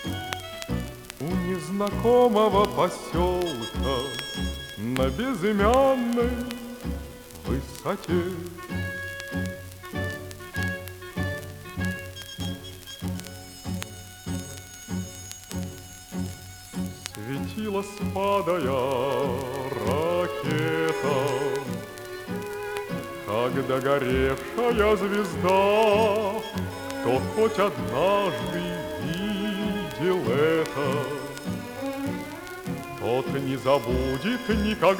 Pop in Russian Pop